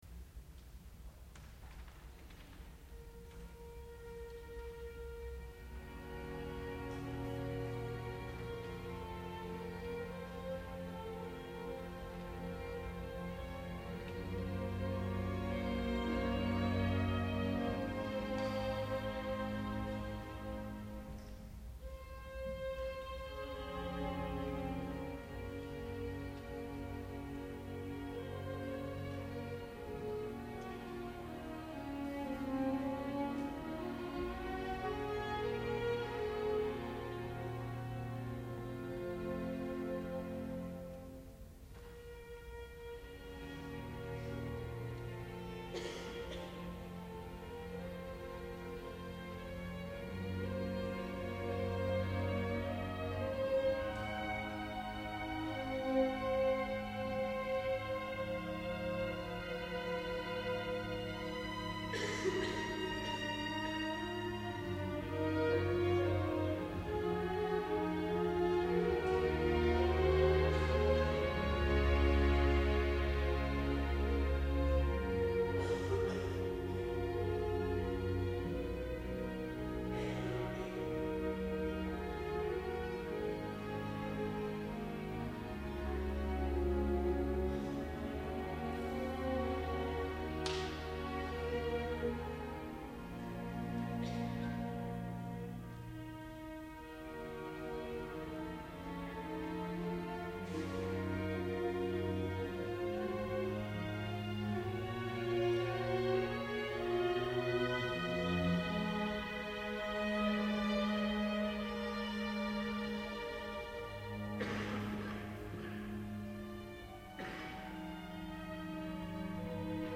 Inspelat i Clara kyrka Stockholm 2000